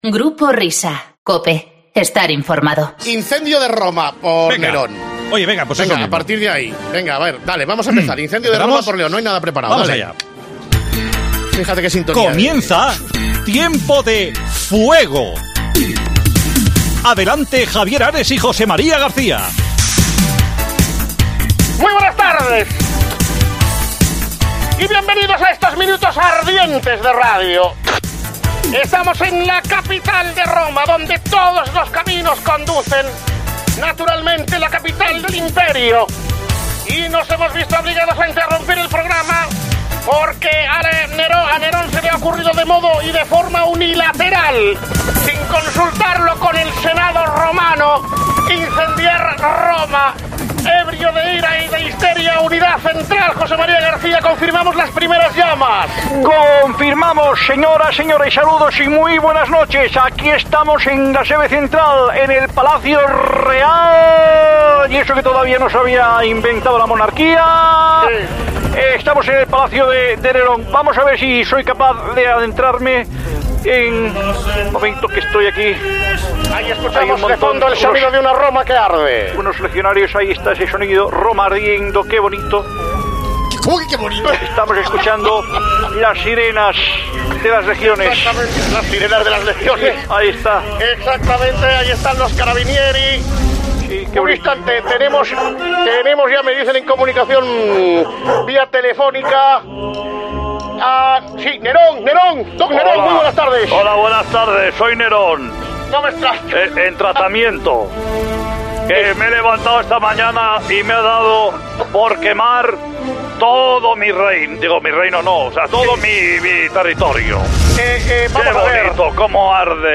Un repaso histórico desde el punto de vista del humor y la buena radio al gran incendio de Roma y al circo romano
Nunca imaginarías haber vivido esta capítulo de la historia en la voz de Javier Ares y José María García.
Y tampoco falta al a cita Julio César, con una voz que recuerda mucho a Florentino Pérez.